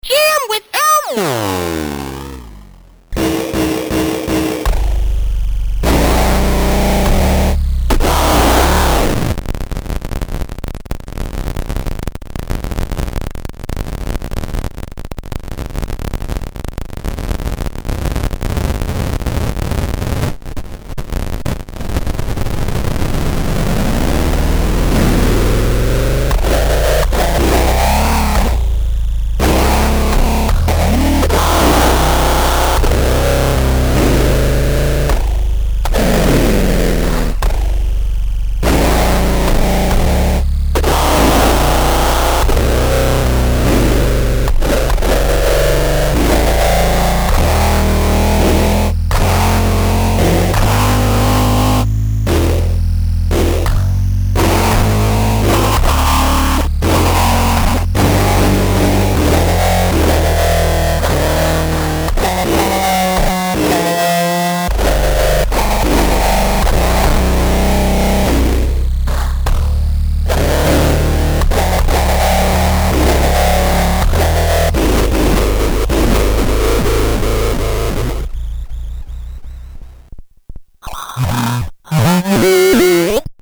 Evil lives within this toy guitar. It speaks with the happy voice of Elmo, and sings exultant songs of Sesame Street.
It now sounds much better than the evil it once spewed. Really slow to really fast.